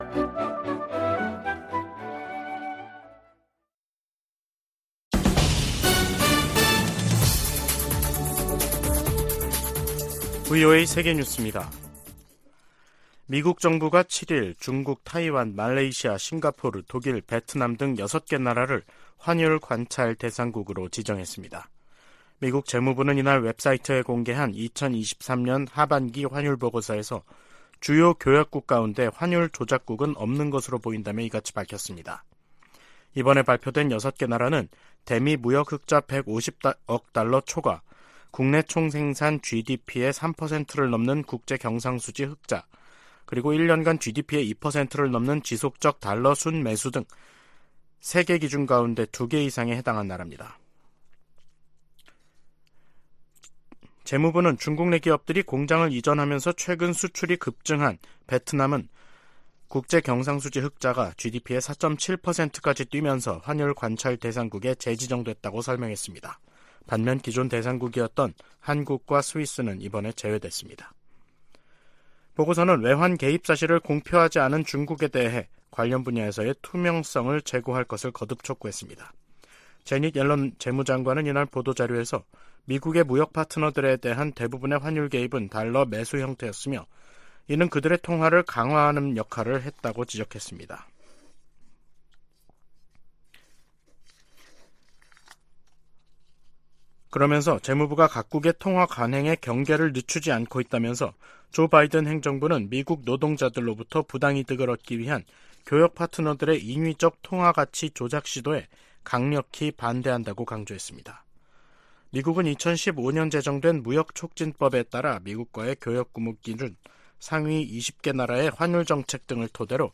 VOA 한국어 간판 뉴스 프로그램 '뉴스 투데이', 2023년 11월 8일 3부 방송입니다.